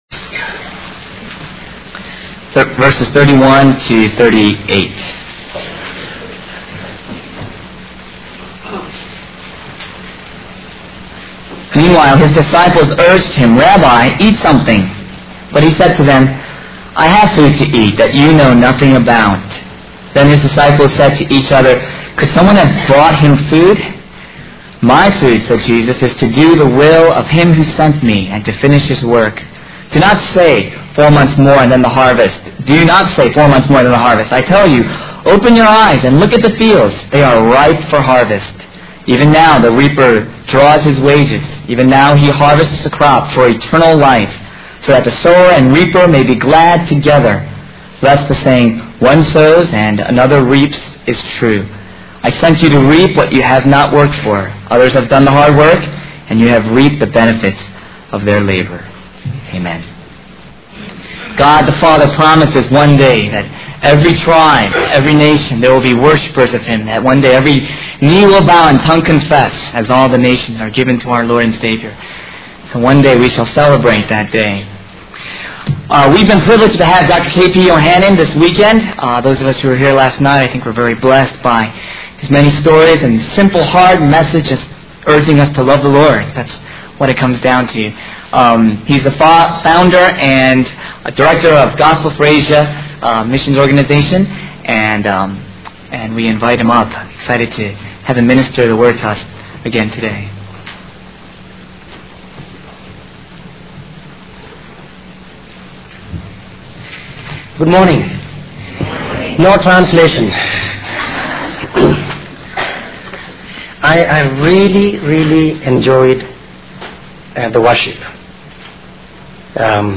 In this sermon, the preacher emphasizes the importance of looking beyond our own worldly concerns and focusing on the eternal value of spreading the gospel. He uses the analogy of rice farmers eagerly gathering their harvest to illustrate the urgency of sharing the message of salvation.